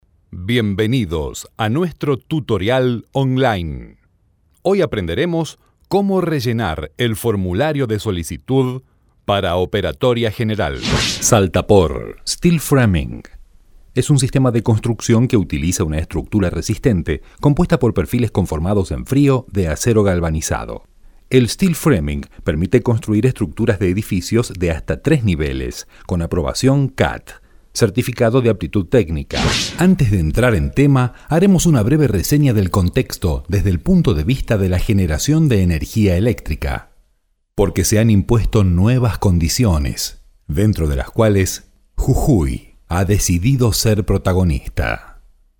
Locutor argentino, español neutro,voz Senior,
Sprechprobe: eLearning (Muttersprache):